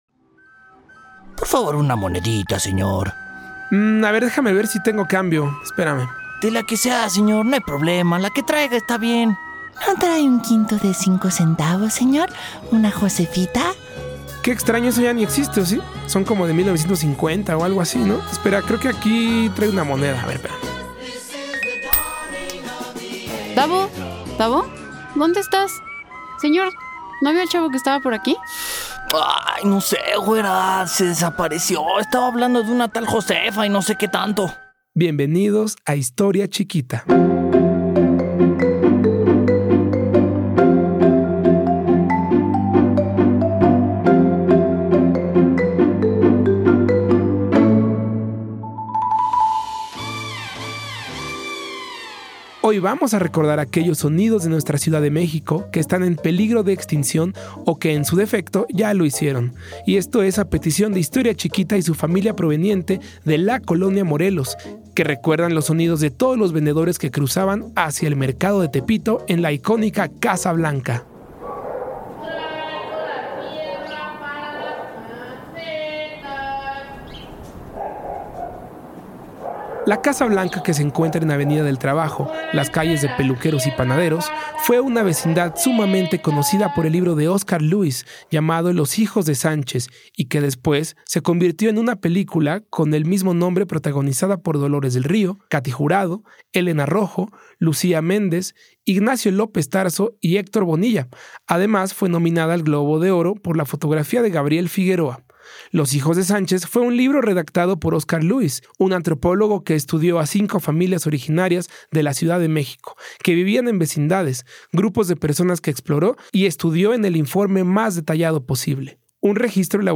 se pierde en medio de un parque en la Ciudad de México